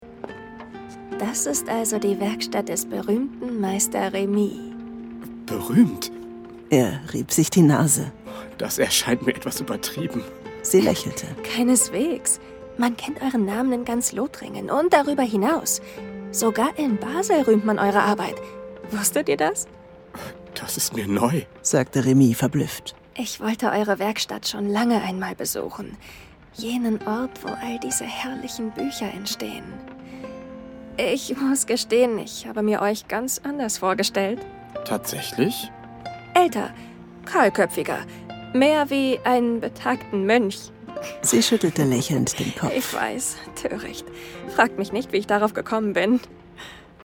plakativ, sehr variabel
Jung (18-30)
Wait Loop (Warteschleife)